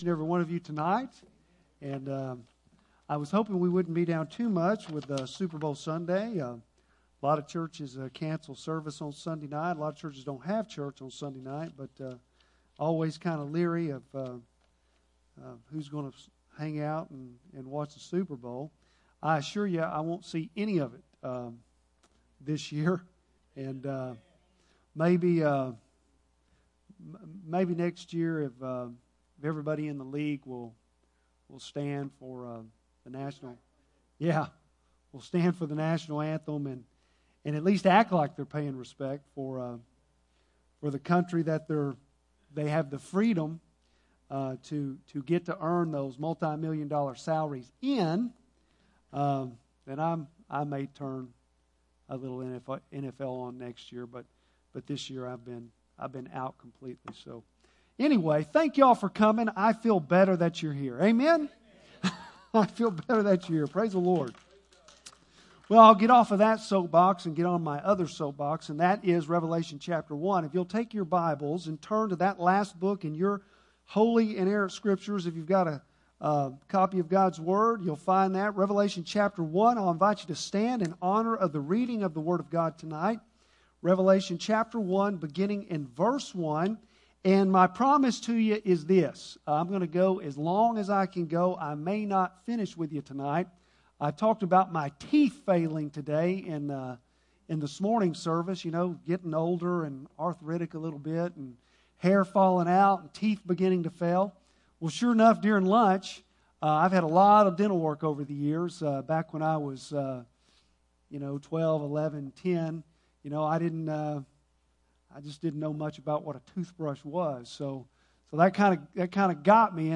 Bible Text: Revelation 1:1-6 | Preacher